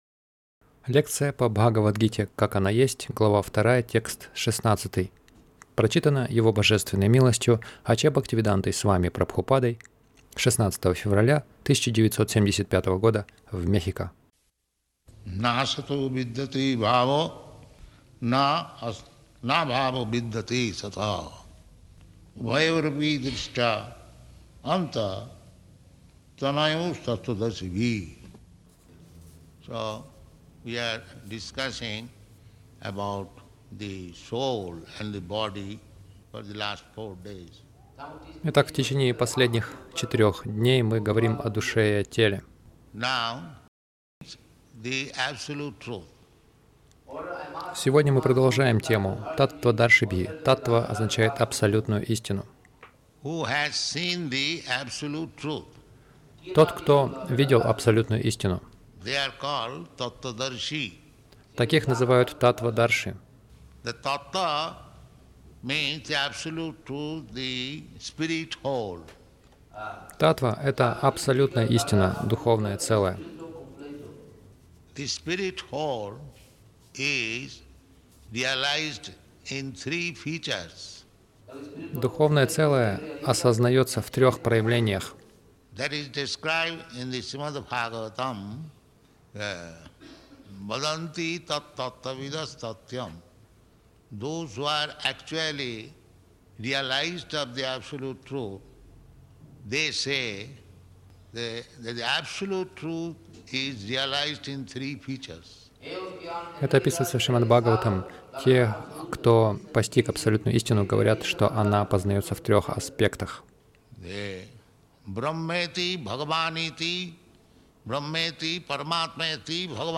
Милость Прабхупады Аудиолекции и книги 16.02.1975 Бхагавад Гита | Мехико БГ 02.16 — Духовные знания — это свет Загрузка...